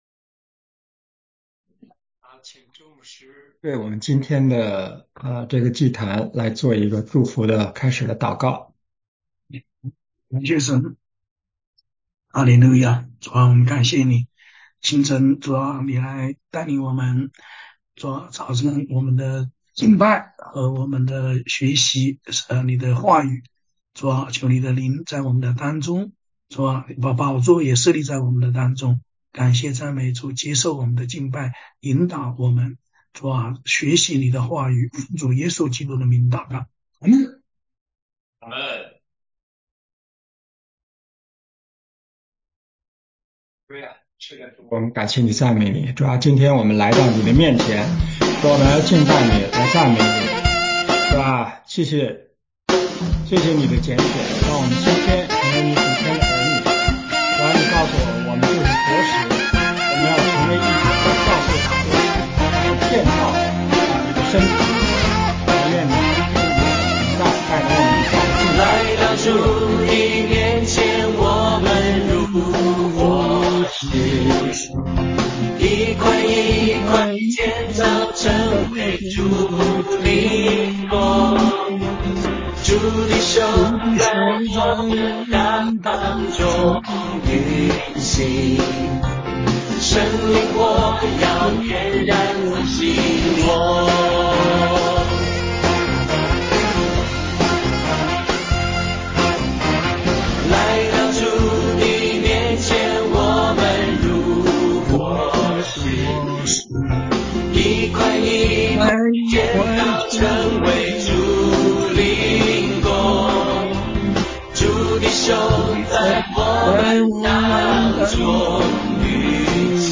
晨祷